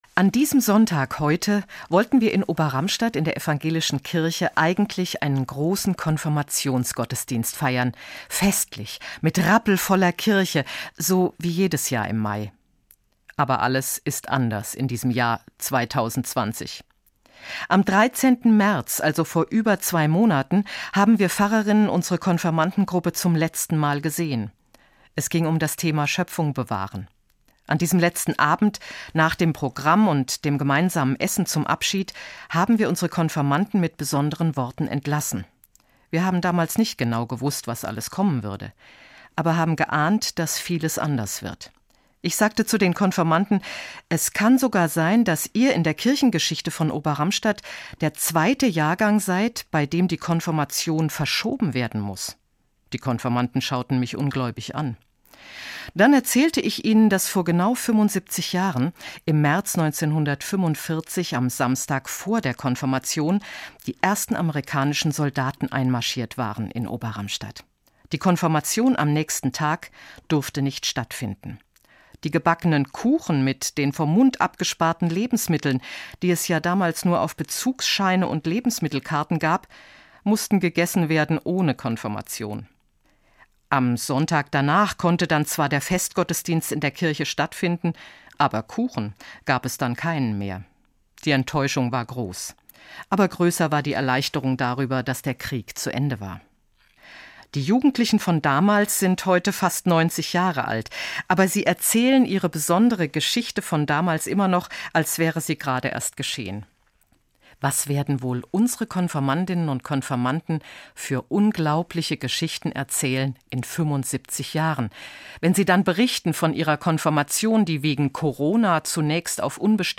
hr2 MORGENFEIER